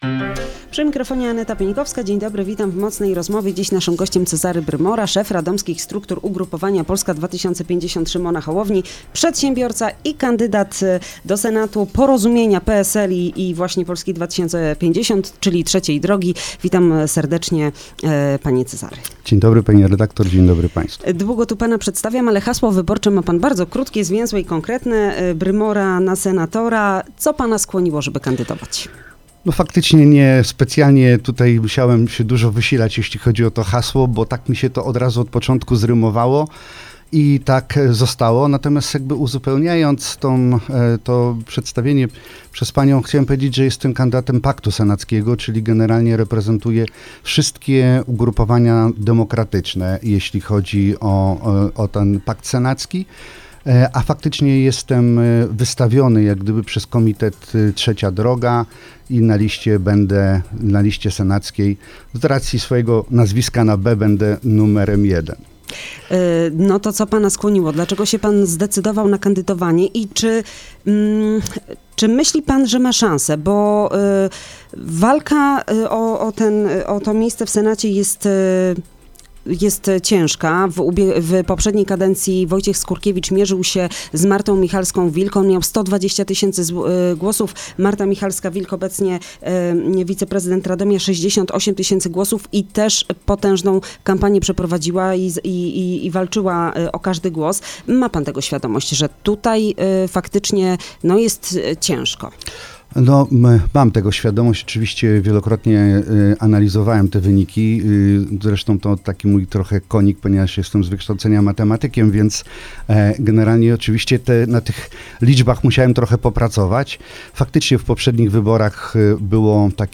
Rozmowa dostępna również na facebookowym profilu Radia Radom: